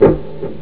FUNKYG elecgtfx.wav